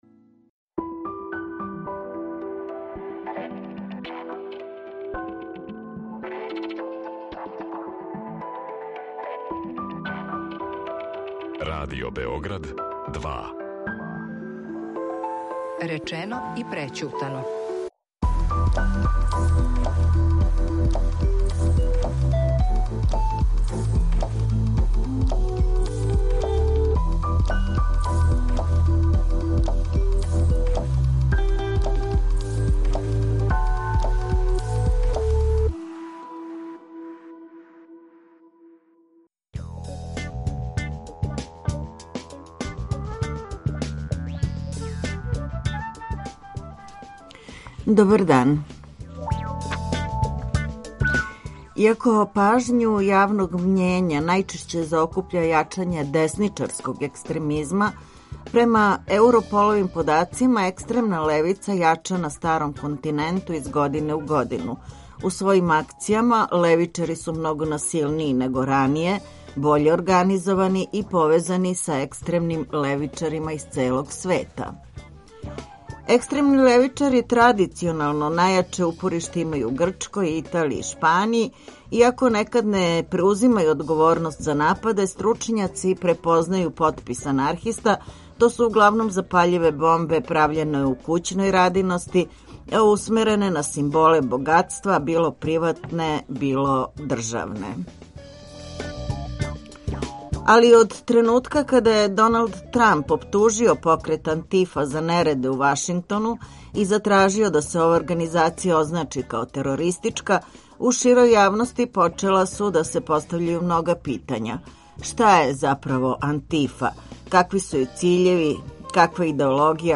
Гости су млади социолози